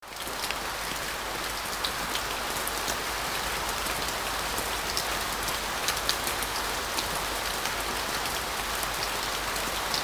rain01.mp3